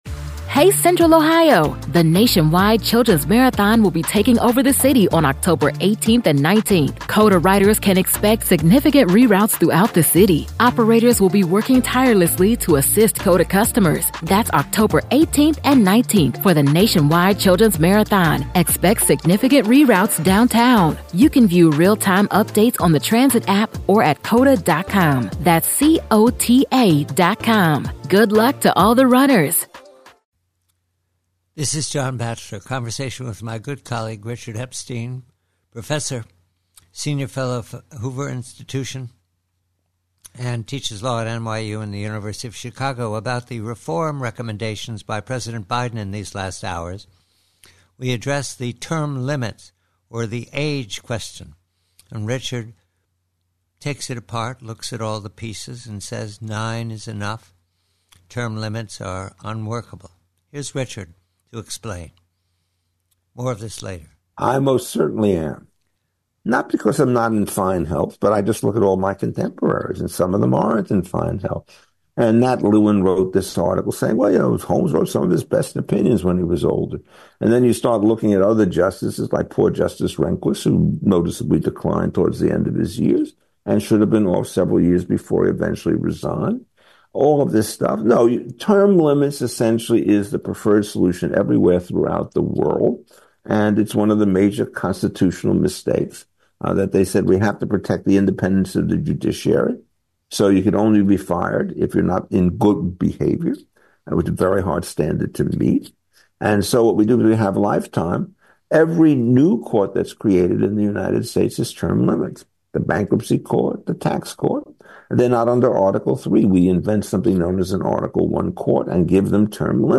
PREVIEW: SCOTUS: Conversation with colleague Professor Richard Epstein re the Biden Administration reforms to term limit Justices, enforce a code of ethics and rto everse the immunity decision for POTUS & What can be done?